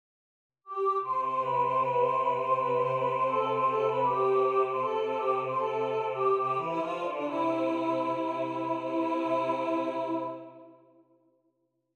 The melody to this song is rather quick than slow; and holds a medium between the alt and bass.